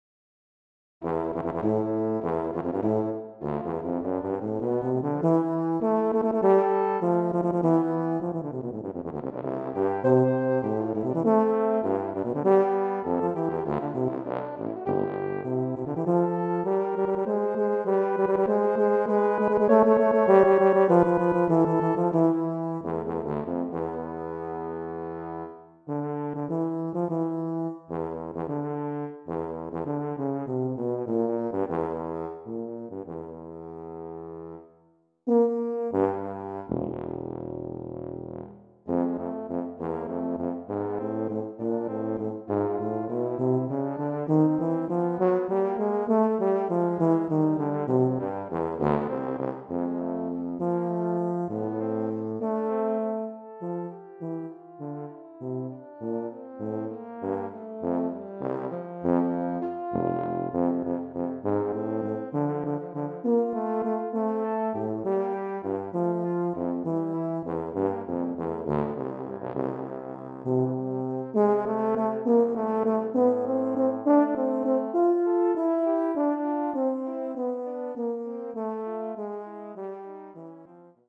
Voicing: Euphonium/Tuba Duet